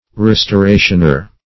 Restorationer \Res`to*ra"tion*er\ (-?r), n.